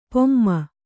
♪ pomma